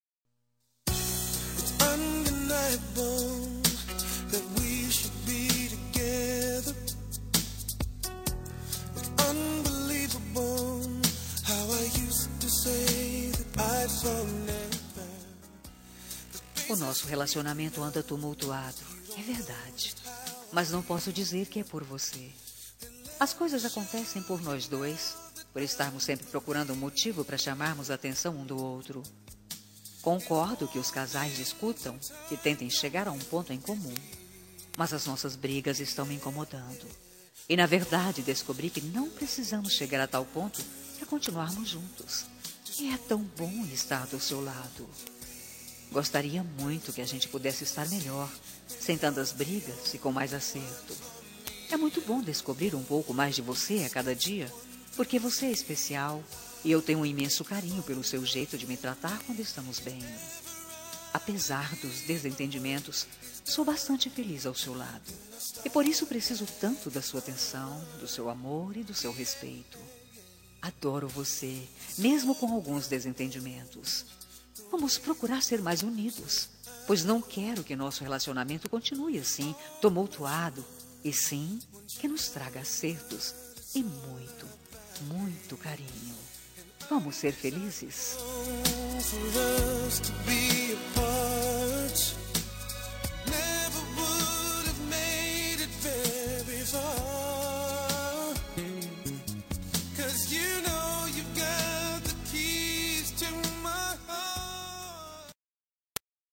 Telemensagem Relacionamento Crise – Voz Feminina – Cód: 5434